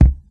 • 70's Tight Low End Fusion Steel Kick Drum Sound C Key 629.wav
Royality free kickdrum sample tuned to the C note. Loudest frequency: 112Hz